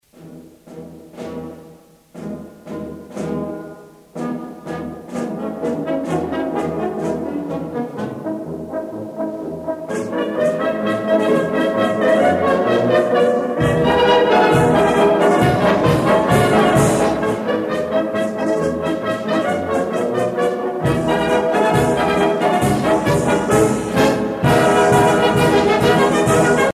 Fonction d'après l'analyste gestuel : à marcher
Pièce musicale éditée